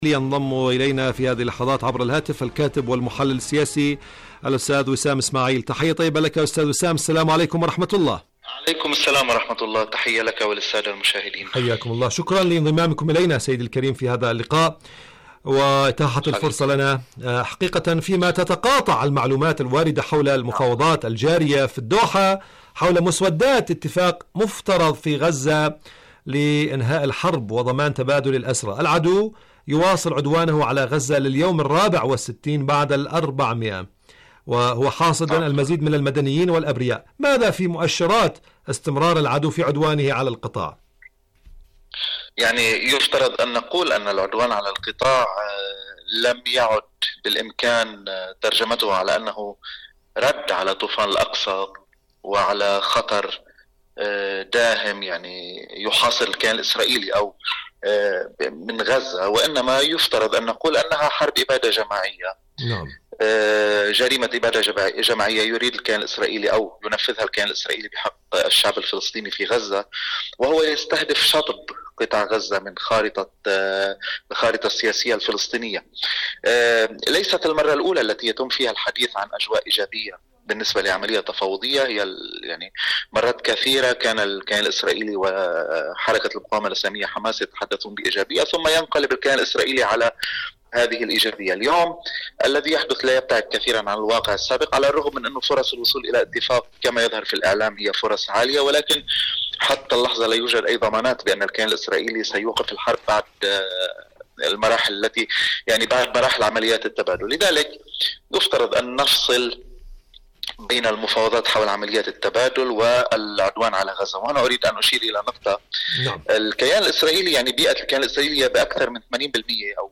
فلسطين اليوم: مقابلة إذاعية